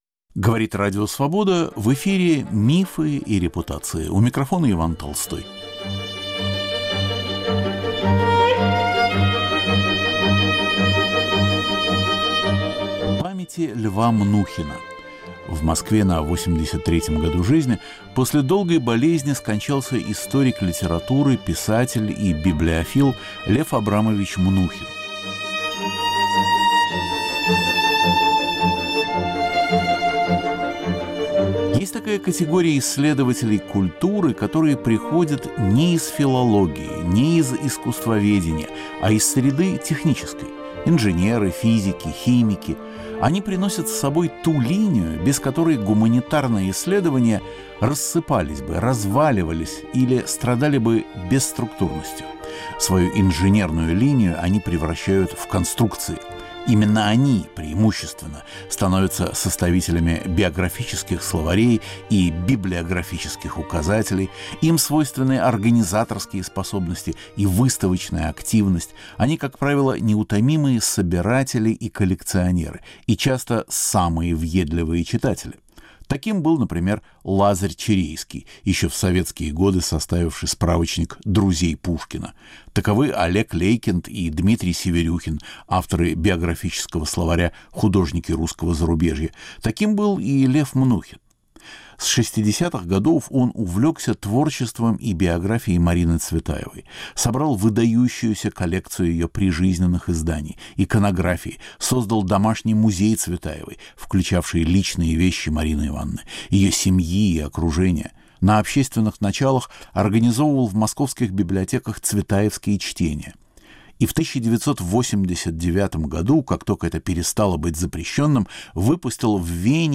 Памяти ученого мы повторяем беседу с ним и его коллегами 2012 года.